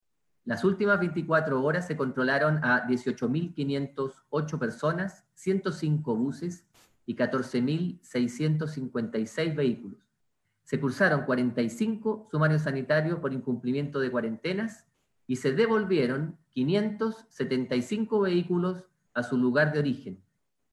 Por su parte, el jefe de la Defensa Nacional de la región, Yerko Marcic, dio cuenta de las fiscalizaciones que se realizaron en los 27 puntos de controles sanitarios.